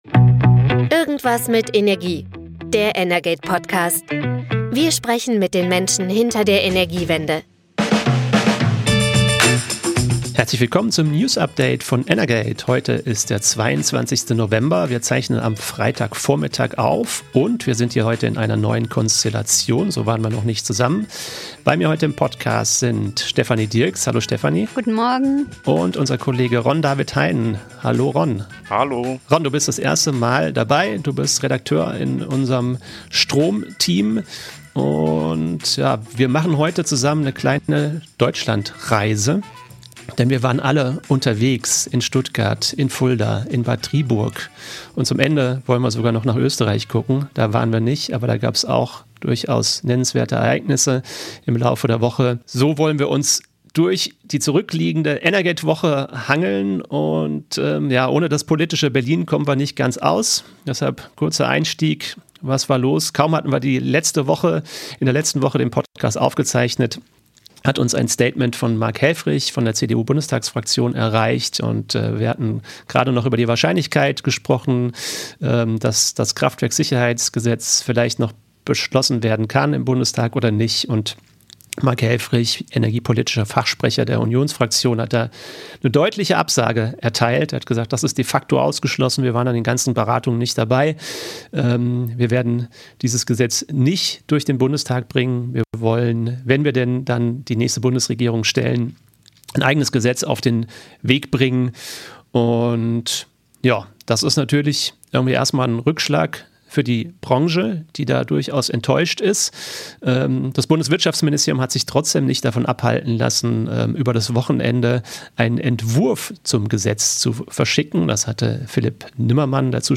Irgendwas mit Energie – der energate-Podcast wendet sich an alle, die sich für die Energiewirtschaft interessieren. Jeden Freitag diskutieren die energate-Politikredakteure die Branchenthemen, die sie besonders bemerkenswert finden – und liefern so einen unterhaltsamen energiepolitischen Wochenrückblick.